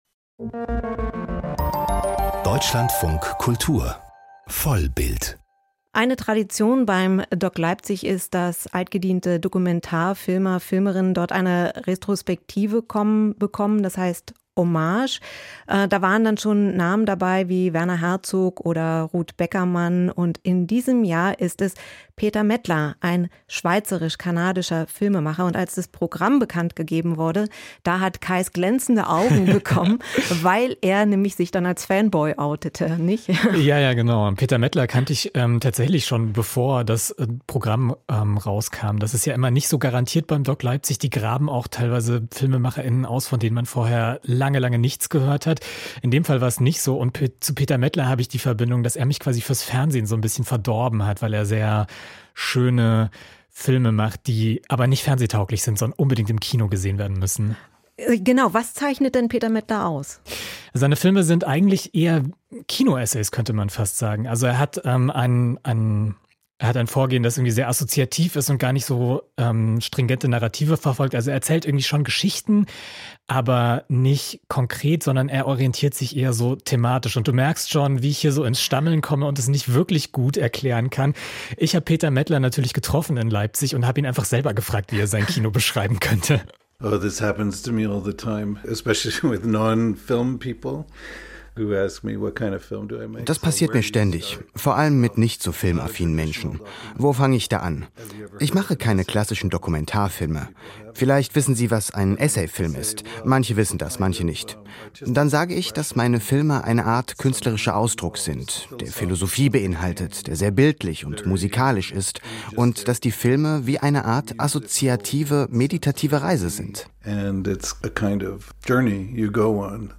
Peter Mettler im Gespräch